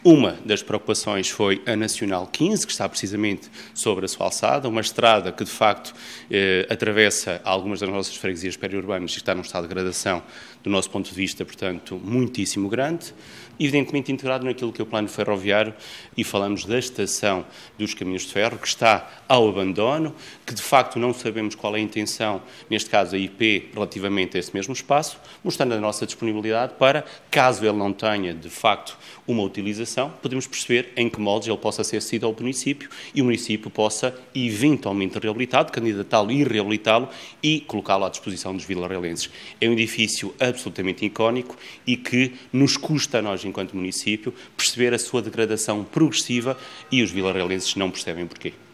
Em Vila Real, o presidente da câmara, o socialista Alexandre Favaios, deixou pedidos ao ministro das Infraestruturas relacionados com a estação ferroviária de Vila Real e com a Estrada Nacional n.º 15: